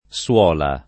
suola [ SU0 la ] s. f.